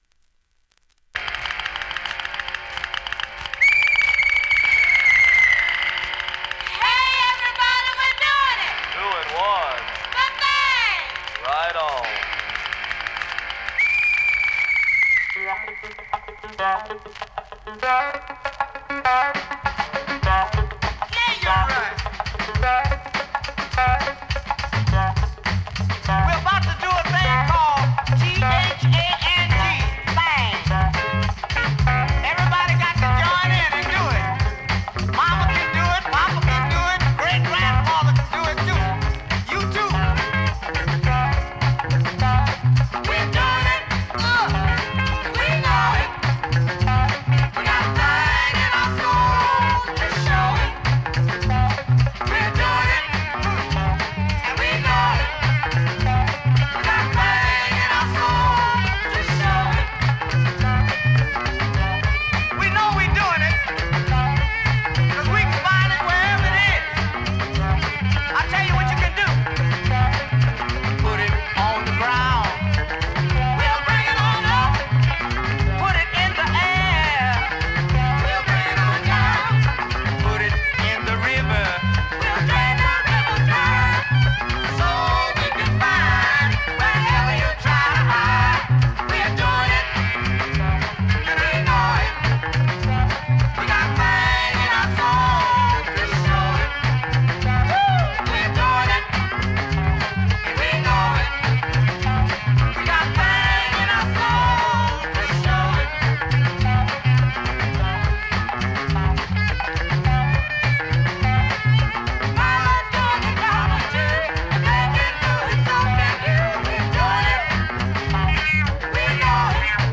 現物の試聴（両面すべて録音時間６分４秒）できます。